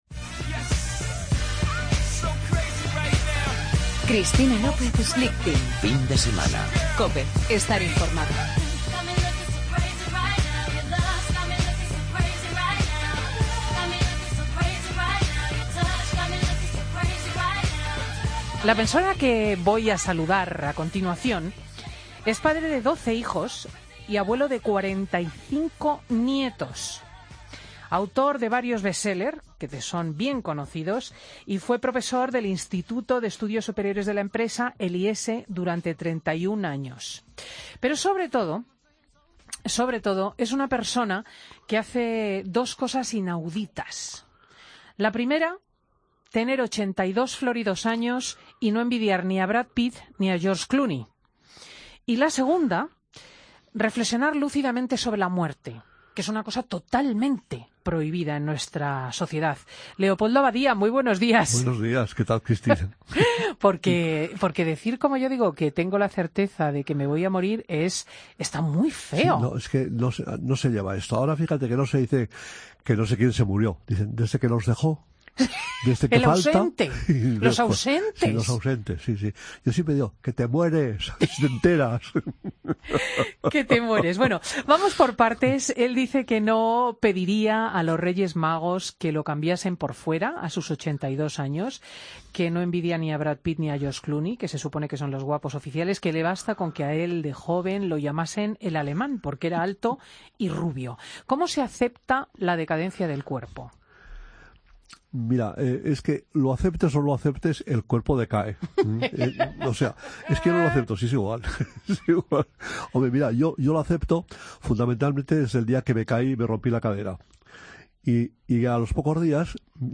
AUDIO: Escucha la entrevista a Leopoldo Abadía, autor de 'Yo de mayor quiero ser joven', en Fin de Semana COPE